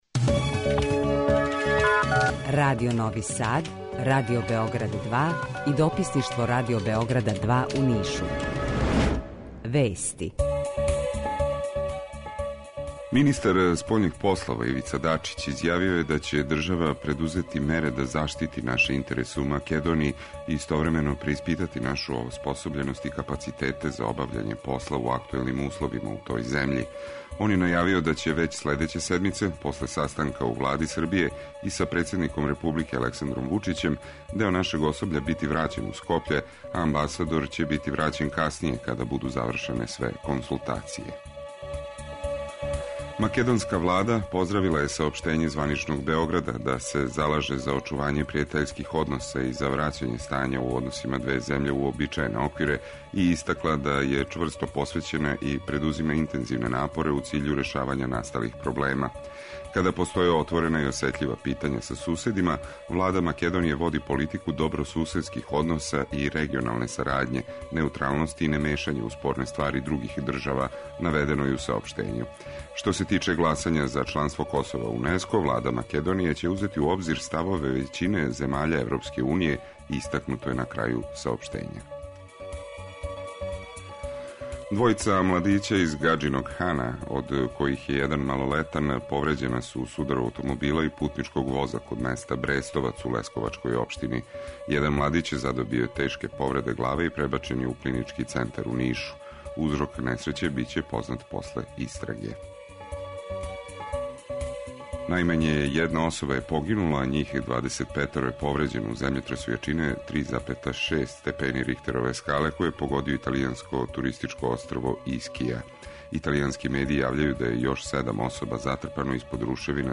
Jутарњи програм заједнички реализују Радио Београд 2, Радио Нови Сад и дописништво Радио Београда из Ниша
У два сата, ту је и добра музика, другачија у односу на остале радио-станице.